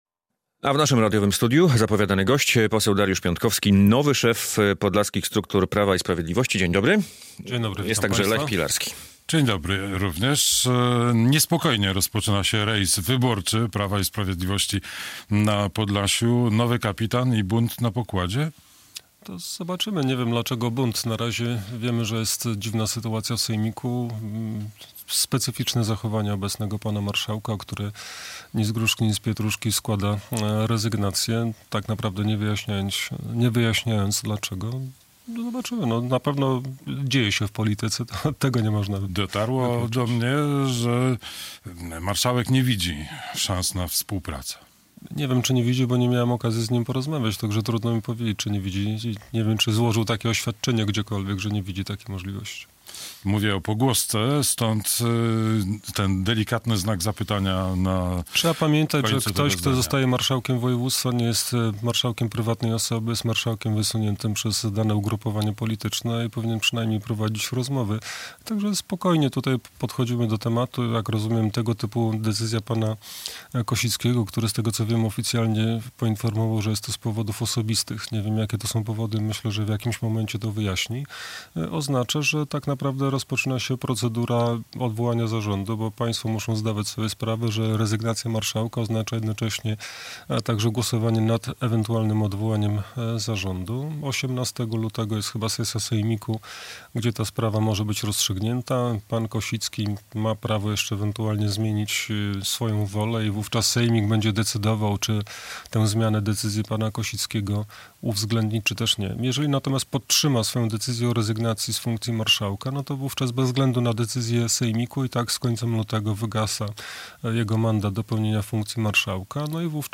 Radio Białystok | Gość | Dariusz Piontkowski [wideo] - pełnomocnik PiS na Podlasiu